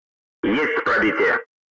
Звук из World of Tanks — пробитие цели